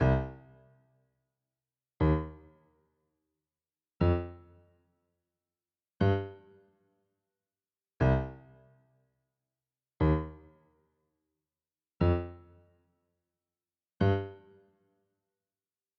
synctest 2-Grand Piano.wav